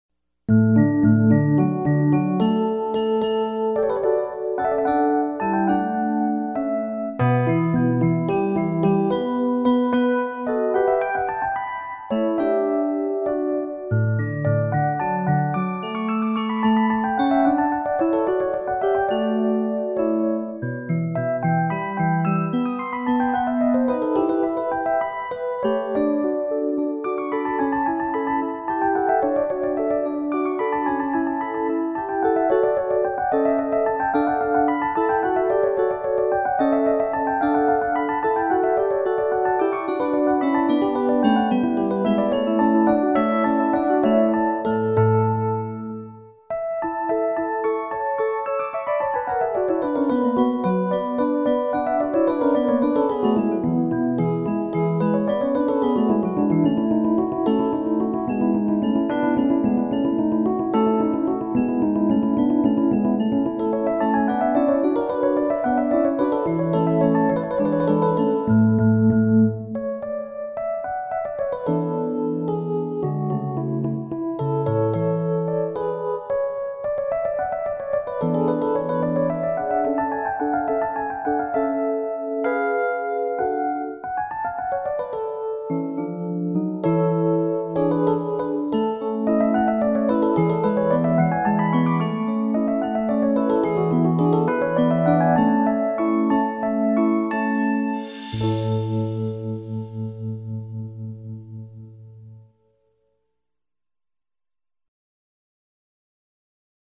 Соната для фортепиано In D, К576 - Вольфганг Амадей Моцарт - слушать
Классическая музыка величайшего композитора для взрослых и детей.
piano-sonata-in-d-k576.mp3